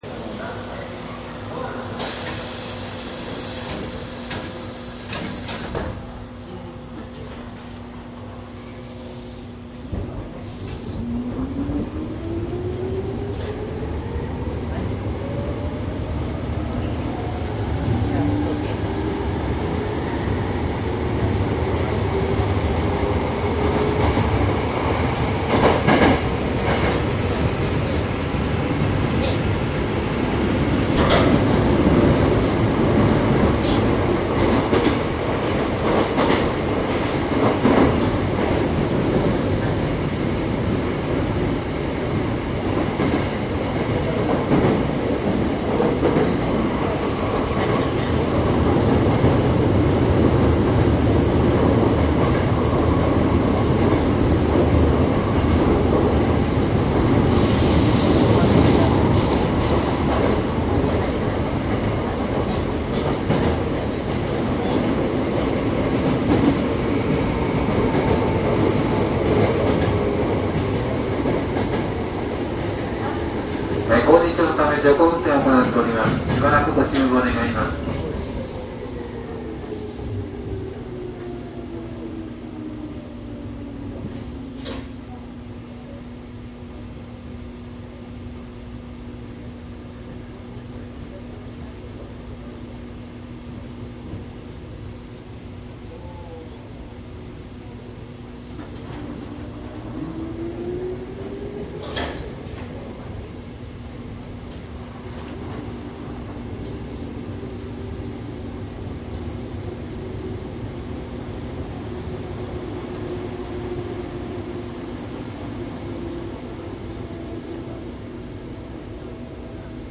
都営浅草線5300系：旧1000形   走行音CD
都営5300系の区間運転と 京急旧1000形にて地下鉄線内の走行音です。
■【各停】泉岳寺→青砥  デハ1243
マスター音源はデジタル44.1kHz16ビット（マイクＥＣＭ959）で、これを編集ソフトでＣＤに焼いたものです。